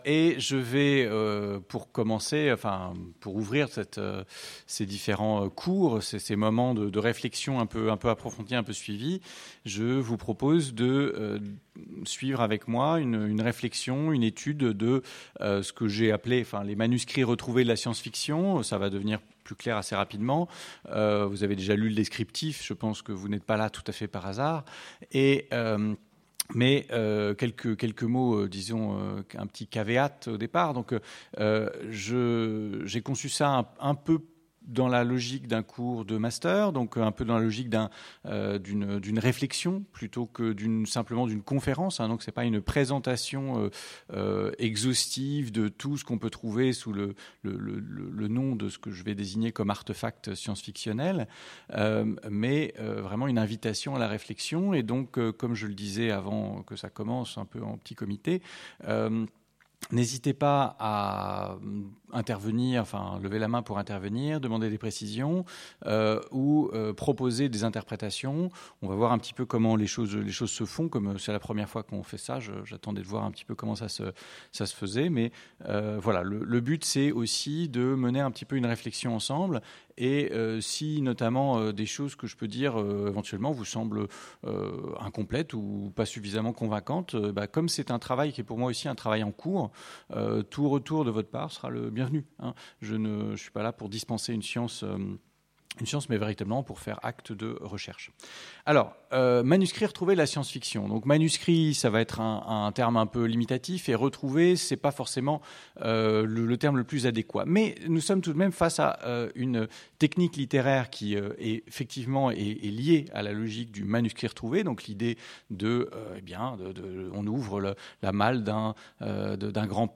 Université éphémère : Les manuscrits retrouvés de la science-fiction enregistrée aux Utopiales 2018